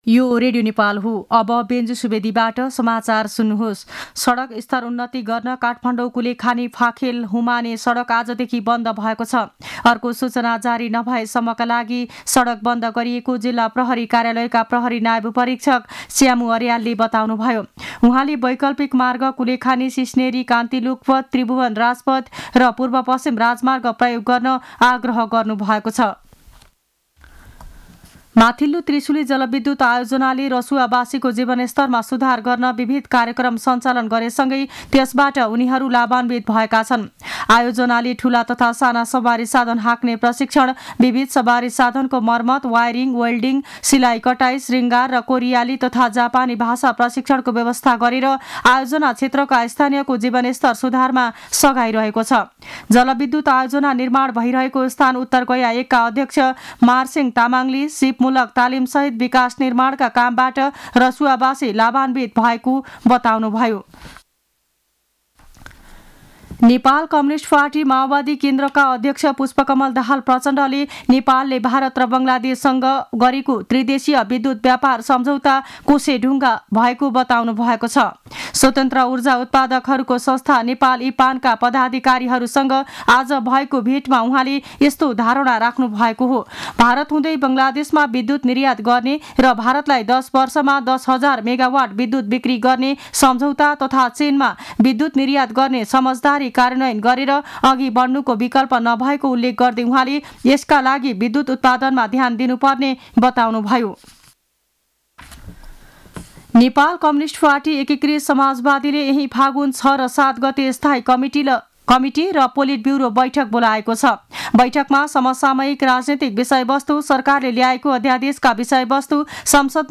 मध्यान्ह १२ बजेको नेपाली समाचार : ३ फागुन , २०८१